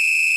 soft-sliderwhistle.ogg